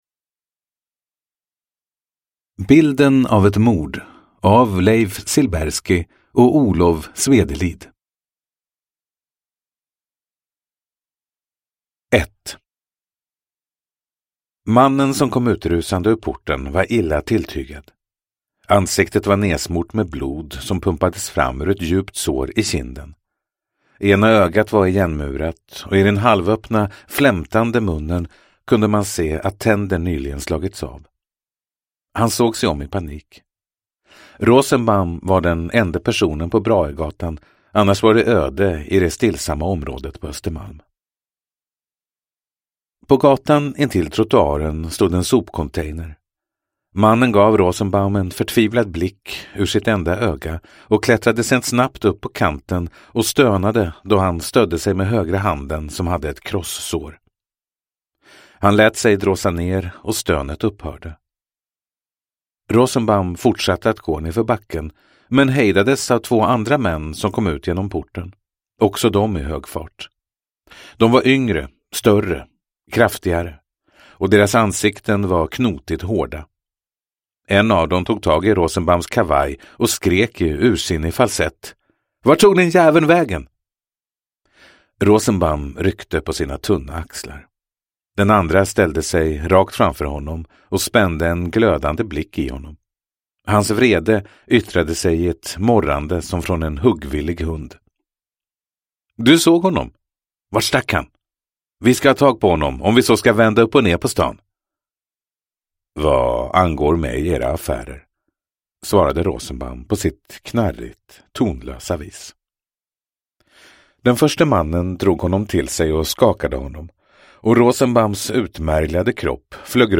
Bilden av ett mord – Ljudbok – Laddas ner